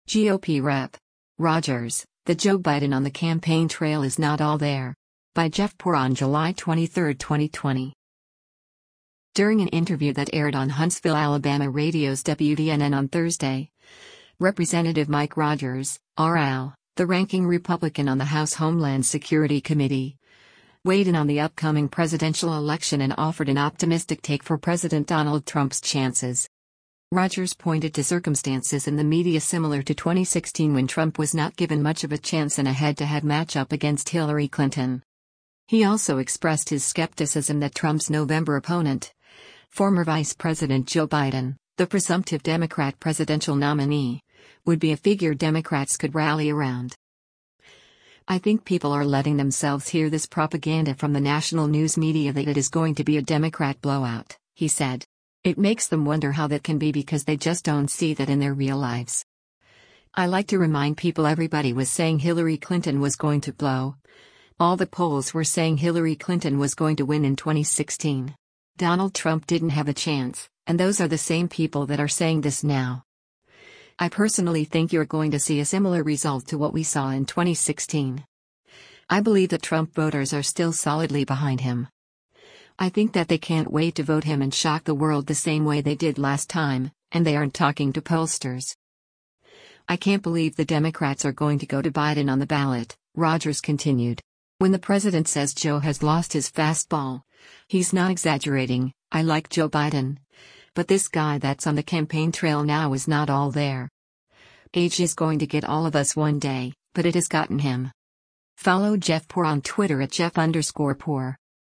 During an interview that aired on Huntsville, AL radio’s WVNN on Thursday, Rep. Mike Rogers (R-AL), the ranking Republican on the House Homeland Security Committee, weighed in on the upcoming presidential election and offered an optimistic take for President Donald Trump’s chances.